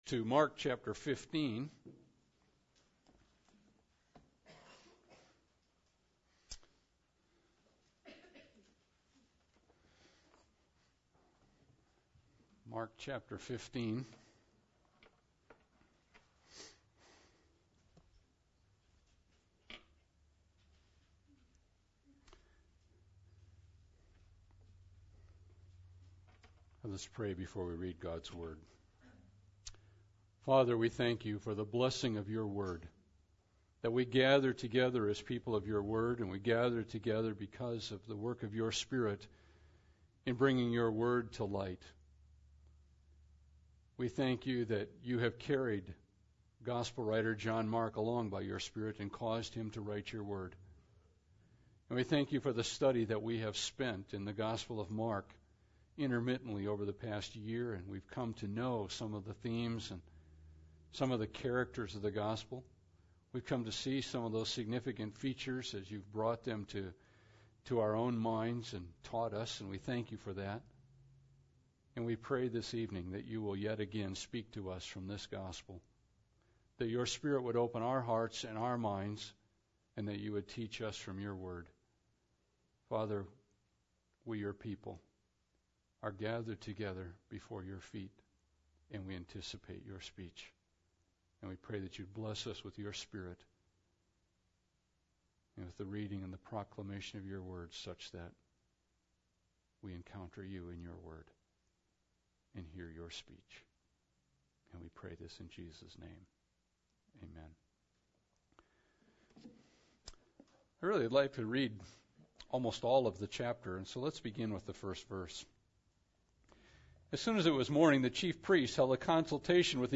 He Gave Himself – Good Friday Service